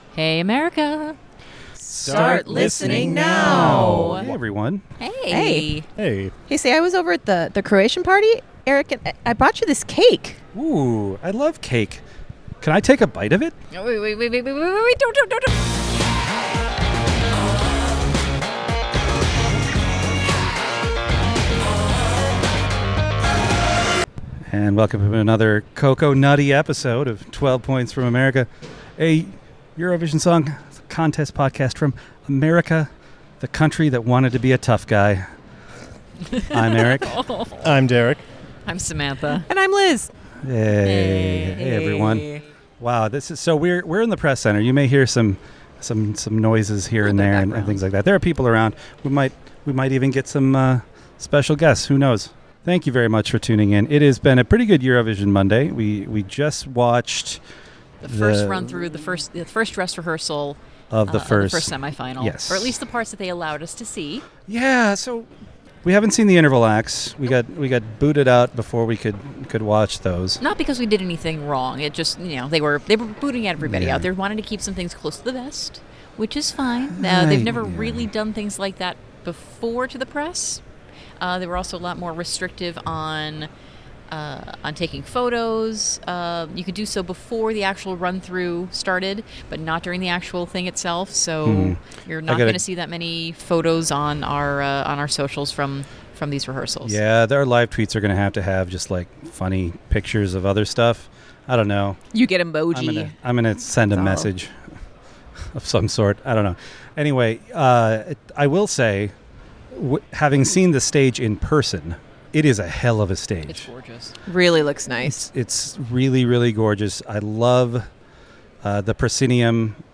12 Points from America is an irreverent and insightful podcast about four best friends coming together to discuss the Eurovision Song Contest.